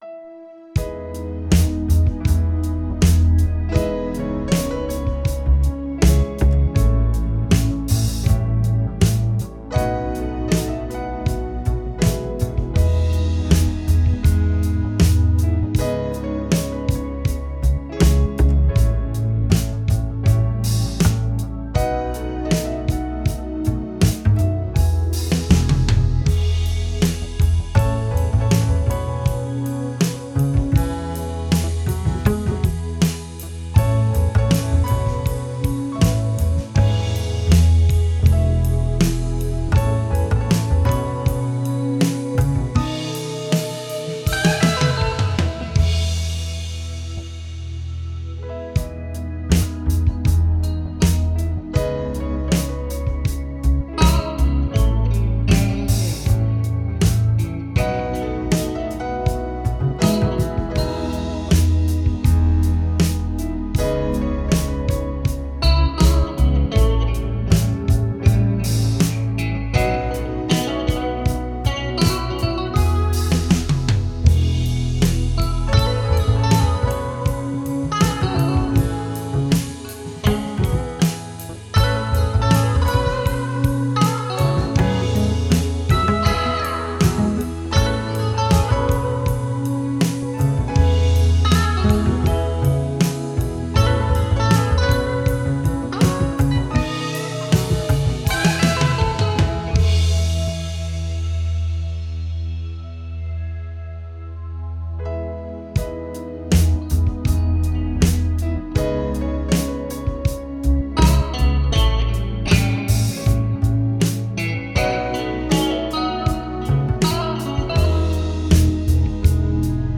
"Ascending" yesterday on a Korg Triton Extreme. I then added Drums from DrumCore 3, a guitar track amplified through IK's Amplitube 3. Then today I added the bass from NI Kontakt version 4.